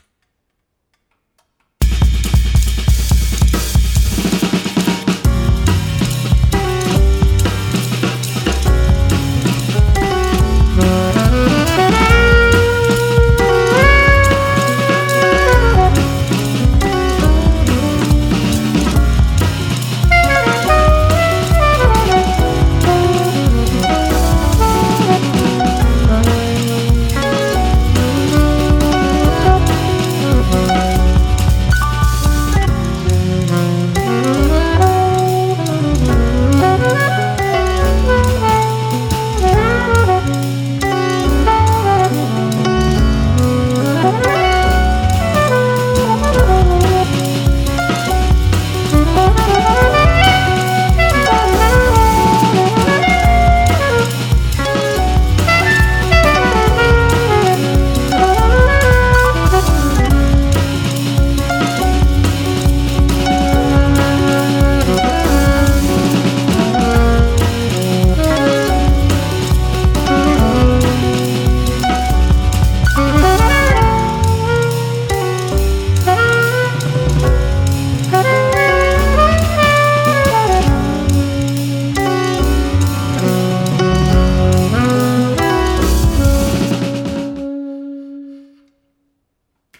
140BPM Jazz improv w Sax
Jazz harmonies and punching rhythm got to in a cowboy-bebop-credit-song mood right from the begining. Elegant and relaxed, the saxophone finds its place quite paradoxically, like a dandy dancing a perfect mambo in the middle of a rave party.
Sounds great! I especially like the places it goes in the second half
Wonderful addition, really beautiful lines and nice saxo sound.